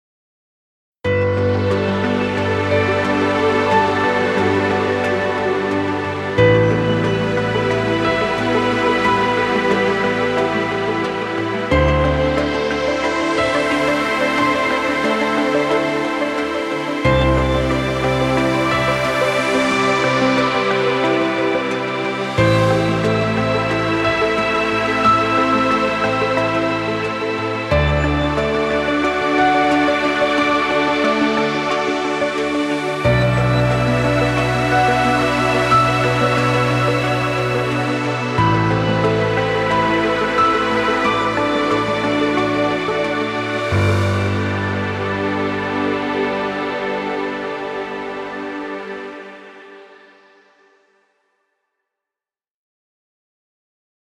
Ambient inspirational music.